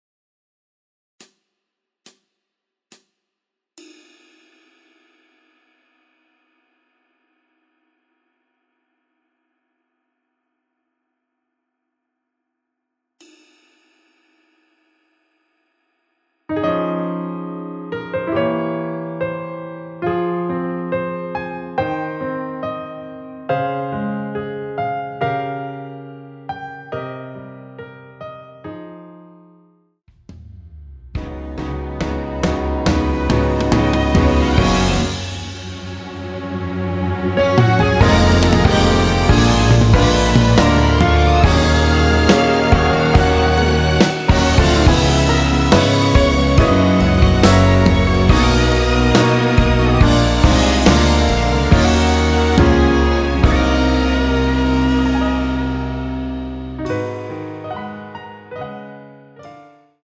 원키에서(-2)내린 MR입니다.
Ab
앞부분30초, 뒷부분30초씩 편집해서 올려 드리고 있습니다.
중간에 음이 끈어지고 다시 나오는 이유는